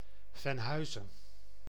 Venhuizen (Dutch: [vɛnˈɦœyzə(n)]